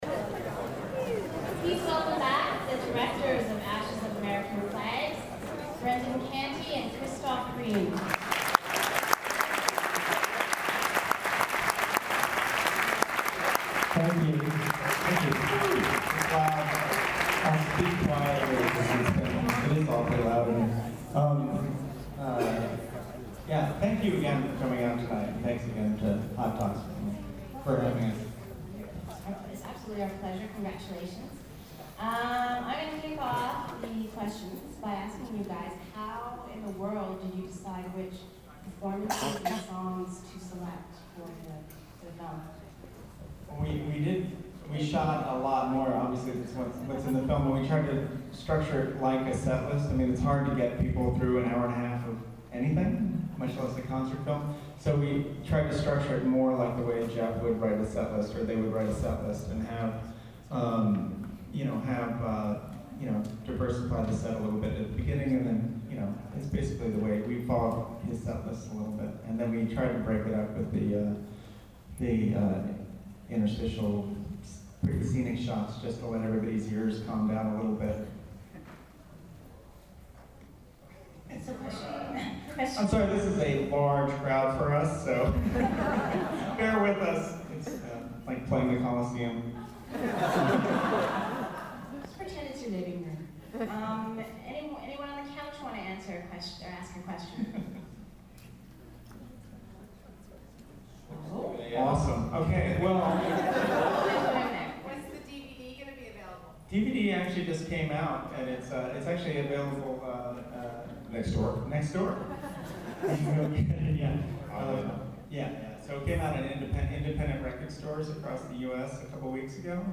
from after the screening:
ashesofamericanflags_qa.mp3